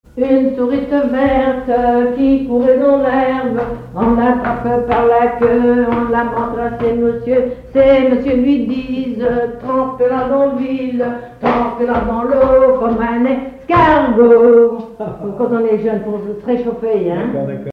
enfantine : comptine
collecte en Vendée
Pièce musicale inédite